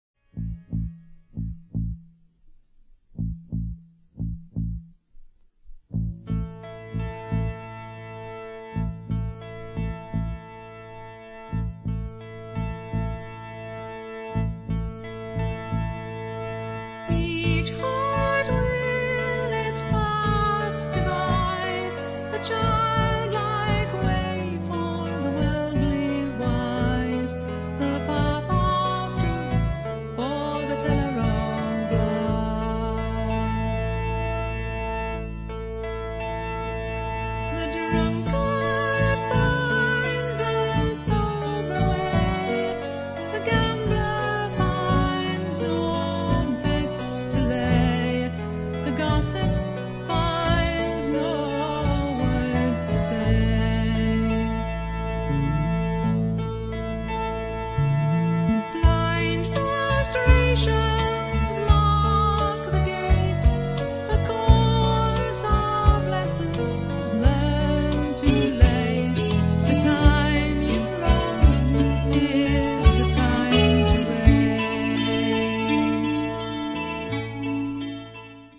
First part, 1:18 sec, mono, 22 Khz, file size: 308 Kb.